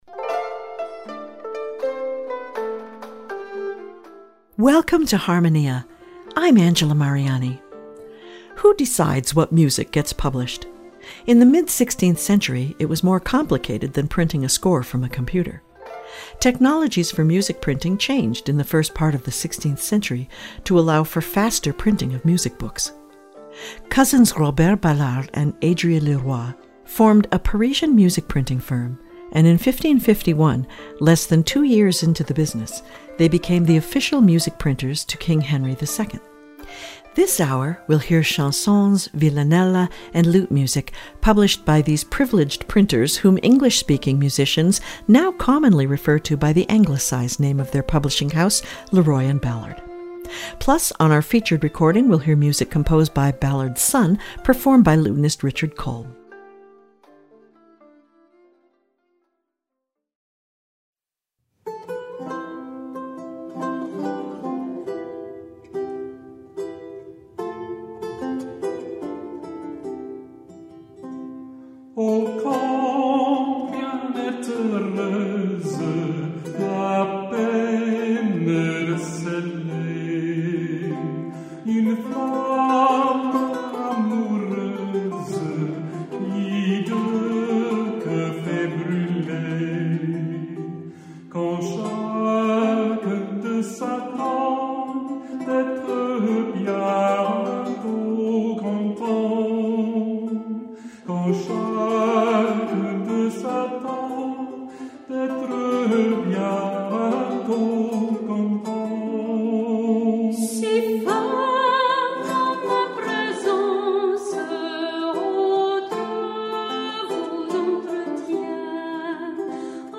This hour, we’ll hear music from 16th century France published by cousins who were the first music printers to receive a royal privilege.